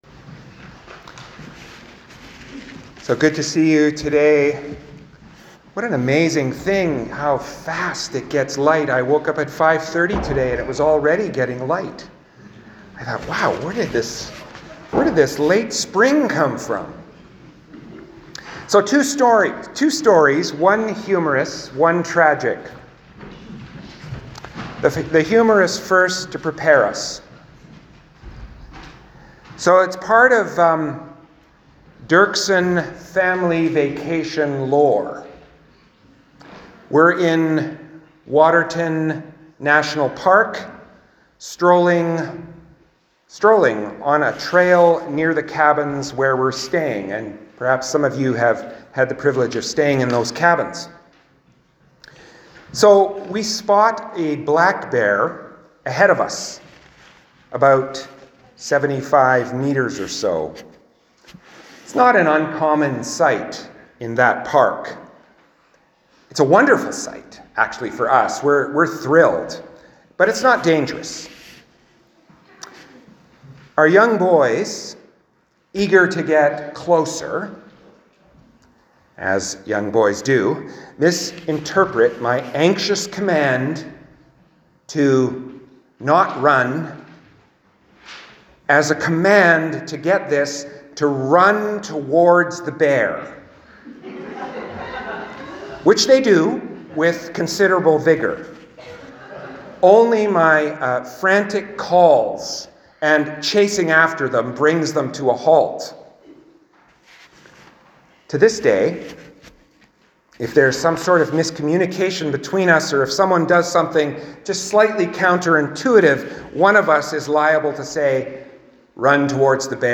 Sermons | St Philip Anglican Church